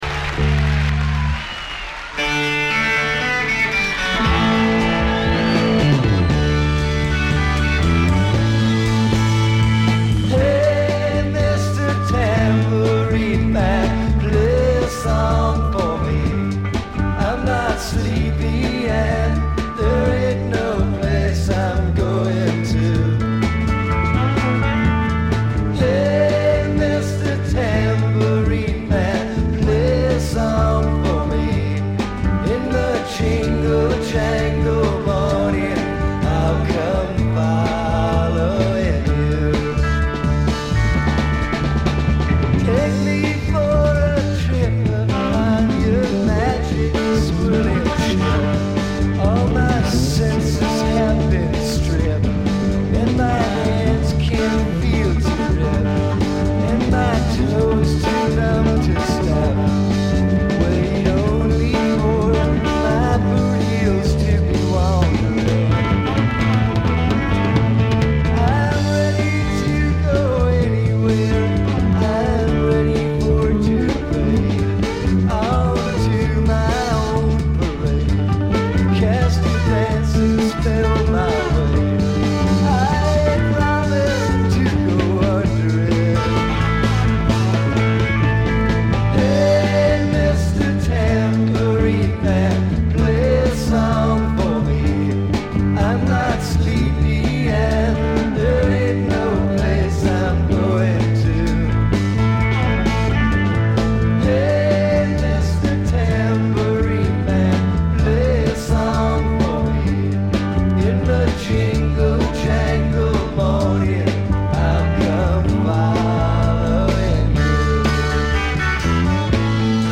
ライヴのAB面はチリプチは出ていると思うのですが音が音だけにほとんどわからない感じです。
試聴曲は現品からの取り込み音源です。
[A] (live)